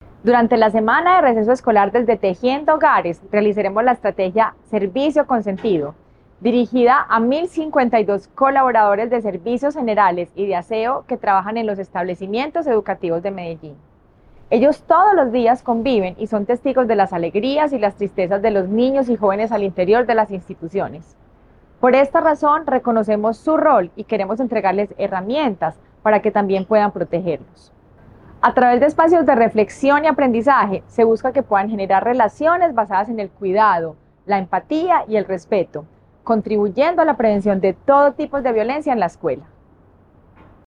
Declaraciones primera Dama, Margarita María Gómez Marín
Declaraciones-primera-Dama-Margarita-Maria-Gomez-Marin.mp3